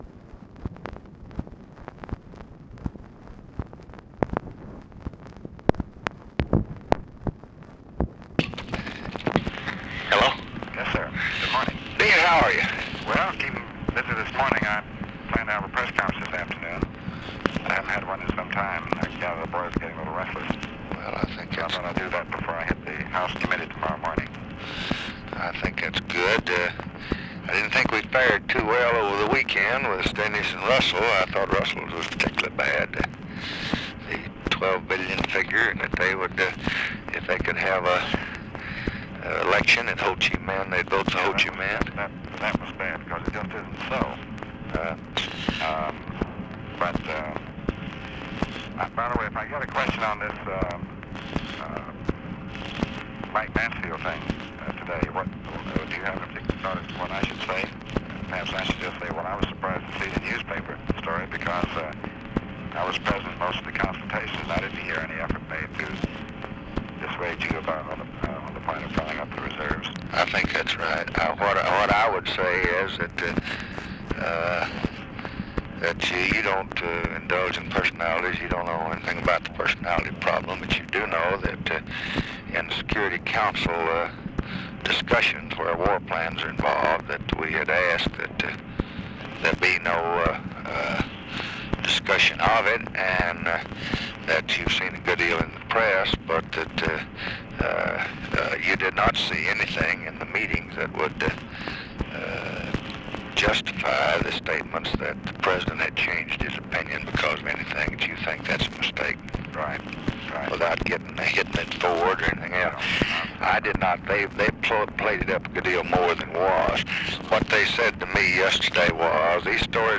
Conversation with DEAN RUSK, August 2, 1965
Secret White House Tapes